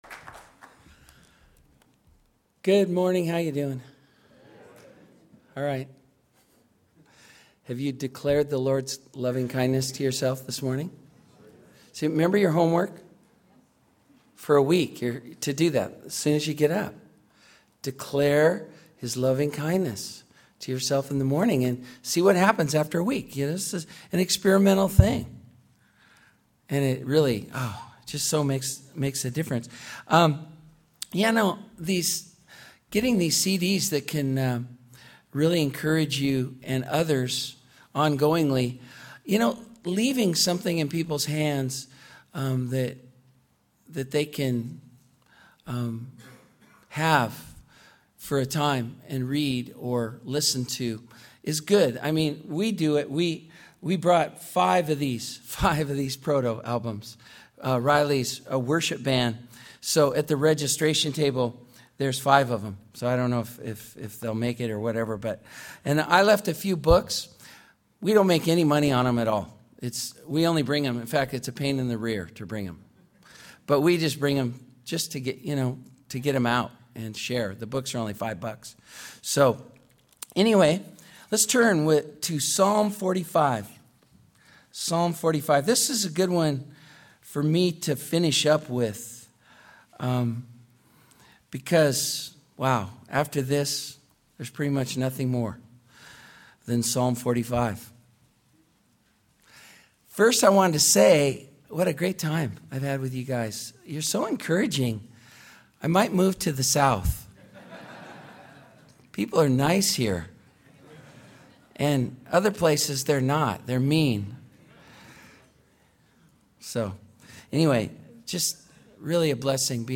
2011 DSPC Conference: Pastors & Leaders Date